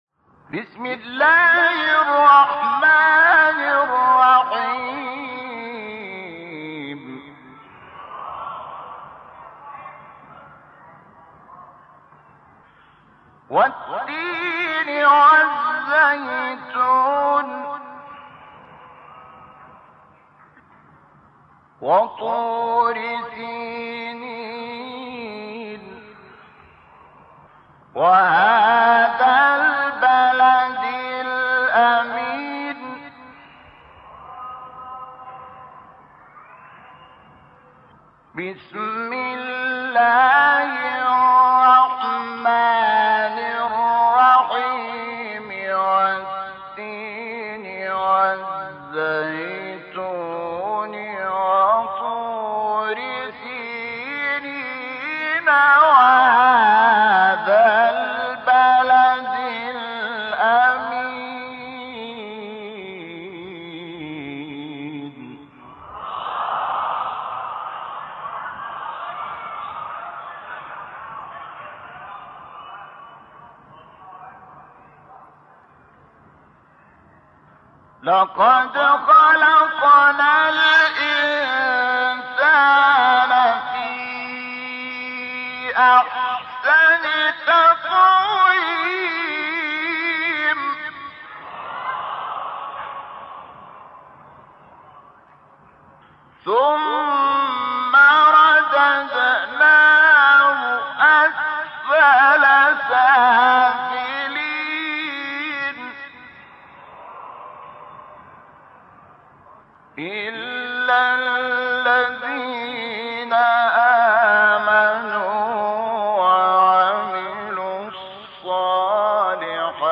تلاوت زیبای سوره تین استاد شحات محمد انور | نغمات قرآن | دانلود تلاوت قرآن